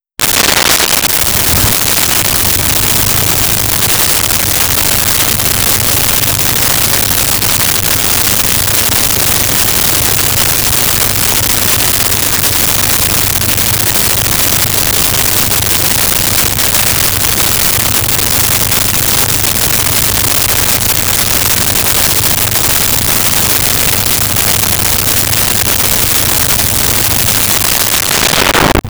Bus Start Idle
Bus Start Idle.wav